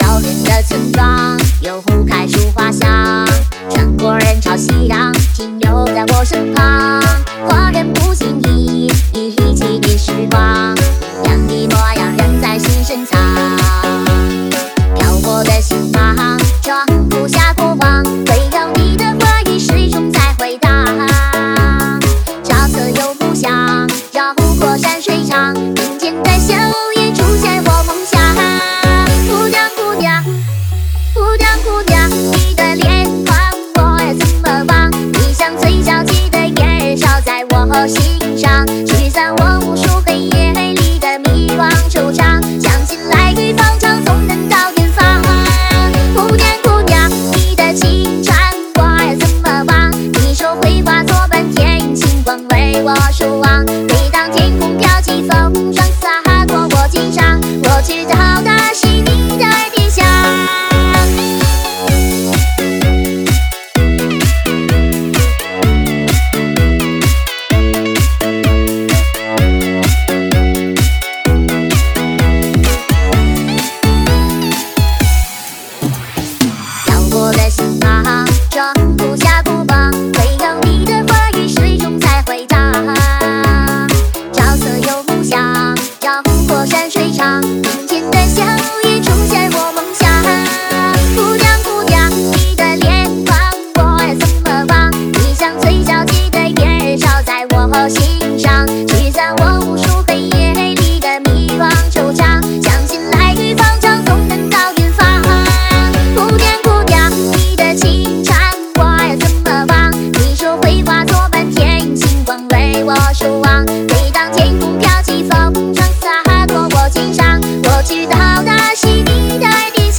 Ps：在线试听为压缩音质节选，体验无损音质请下载完整版
吉他